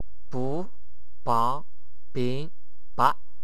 和英语[b]相当。